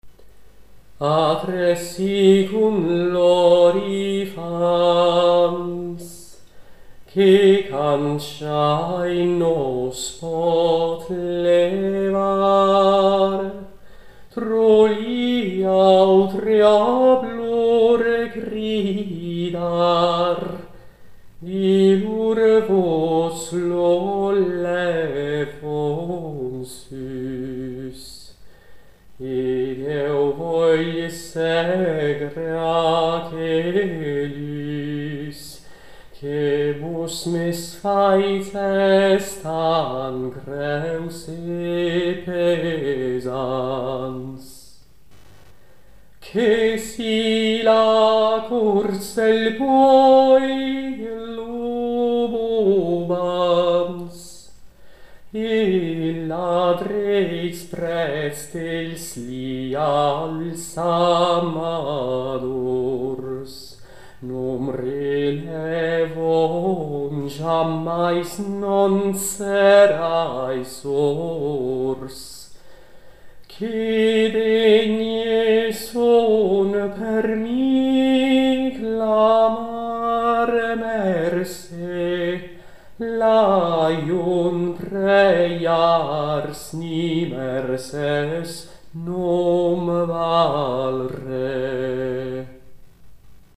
Melodia